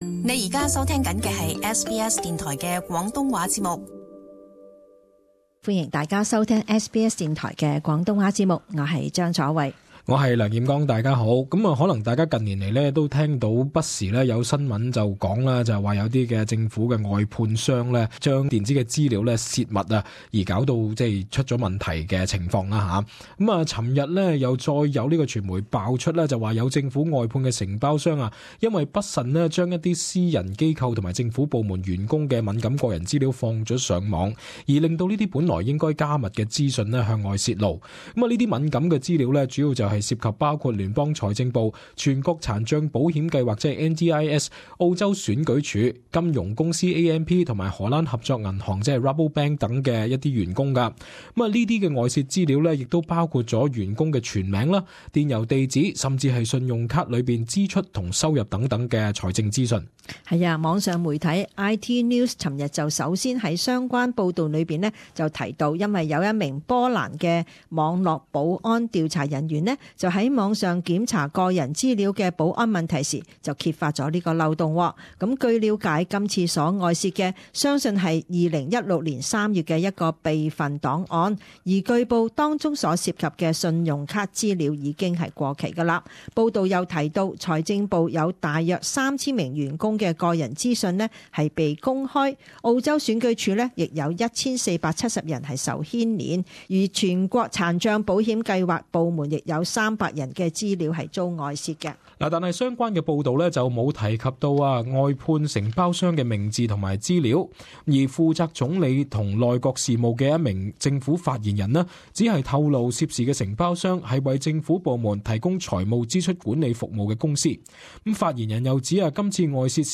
【時事報導】政府外判商洩五萬國民信用卡資料